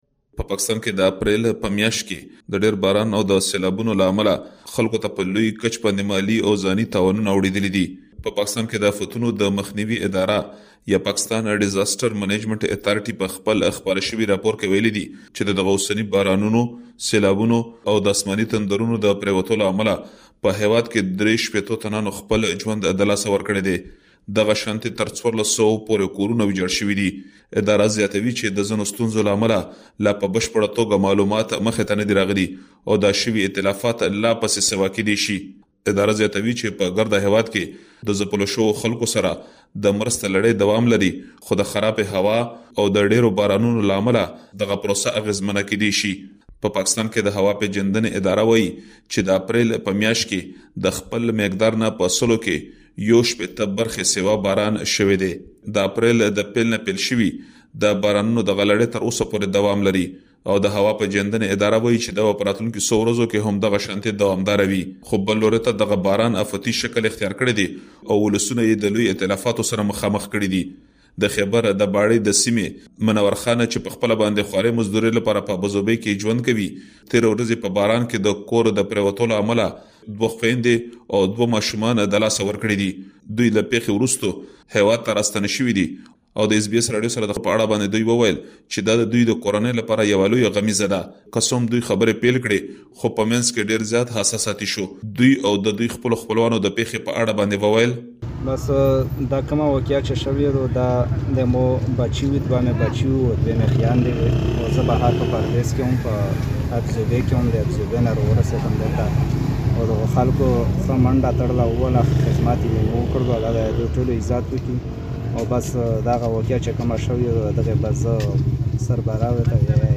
وروستیو سیلابونو په پاکستان کې خلکو ته ځاني او مالي زیانونه اړولي دي. د اس بي اس پښتو خبریال په دې اړه له پاکستان څخه یو رپوټ را استولی دی، مهرباني وکړئ لا ډېر معلومات په رپوټ کې واورئ.